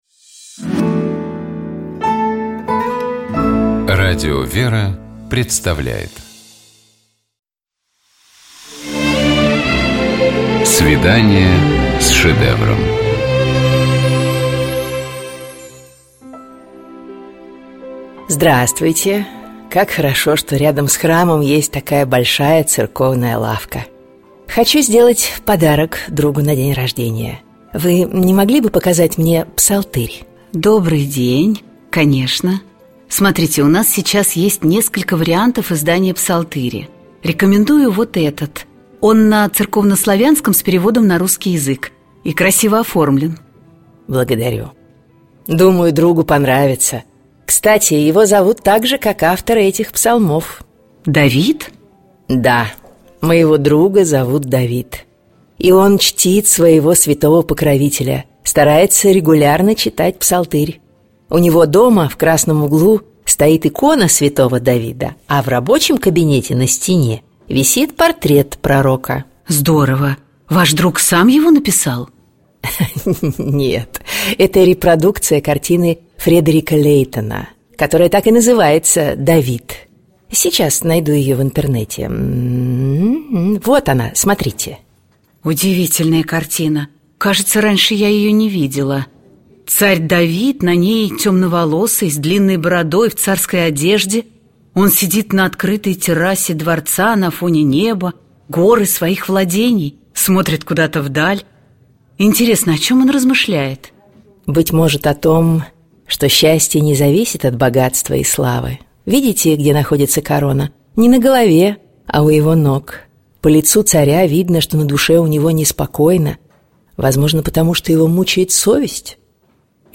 Коридор картиной галереи.